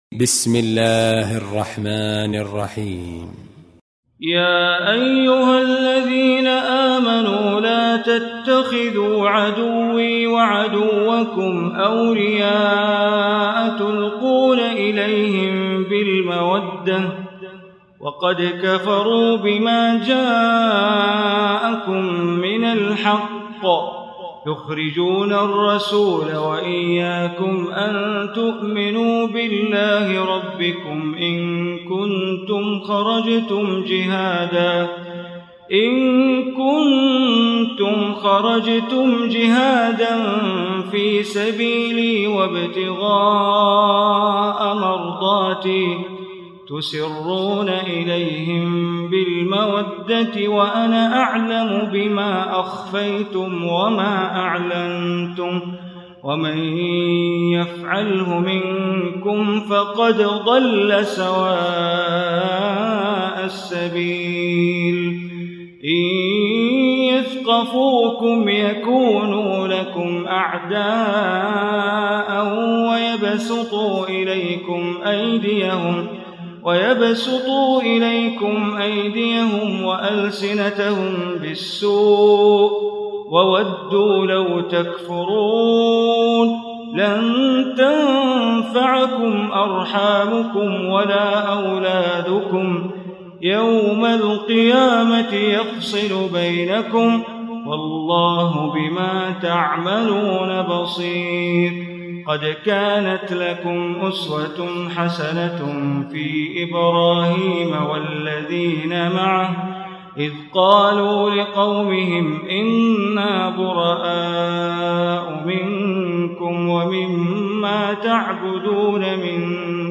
Surah Mumtahanah Recitation by Bandar Baleela
Surah Al-Mumtahanah, listen online mp3 tilawat / recitation in Arabic recited by Imam e Kaaba Sheikh Bandar Baleela.